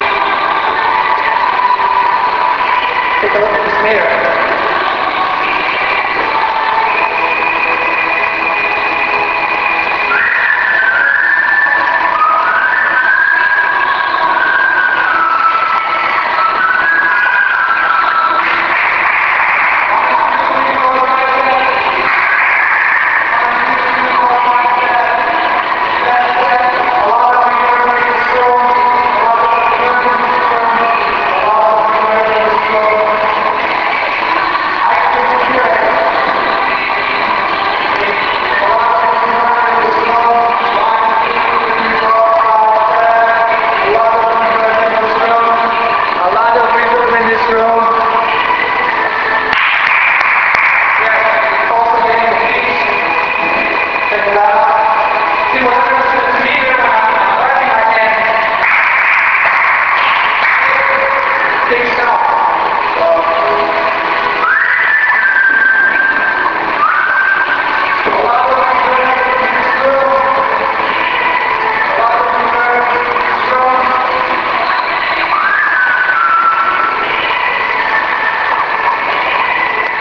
Nella registrazione scaricabile dal link a seguire, è possibile ascoltare un modello SPECTRA LASER M operante in modalità passiva attraverso il vetro della finestra di una sala molto grande, dove si genera un notevole riverbero acustico a causa delle ampie dimensioni:
Una radio FM e' presente nella sala, oltre al soggetto che parla: l'allineamento del sistema e' perfettamente perpendicolare alla finestra ascoltata.
Mtype-alotofreverb.wav